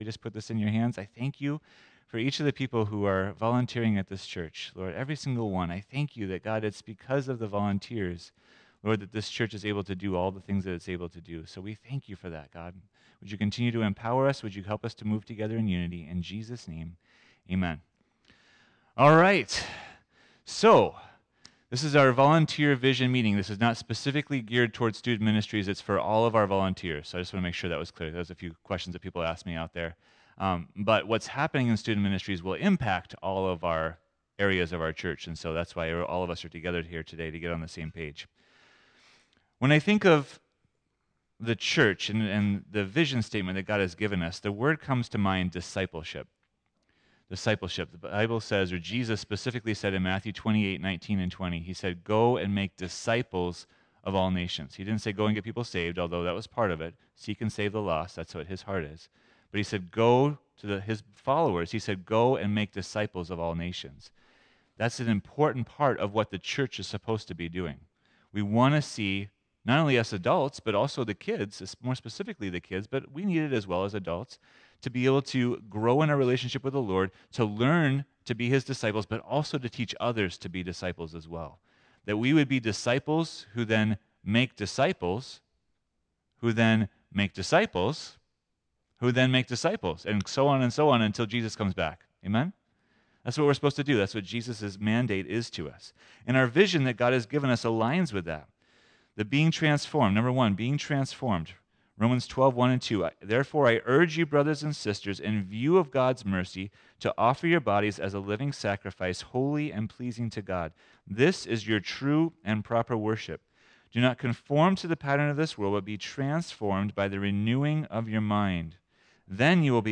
This meeting was presented to the volunteers of Friendship on August 25th as it relates to the direction God is leading our church. Please listen and consider what part God is calling you to do.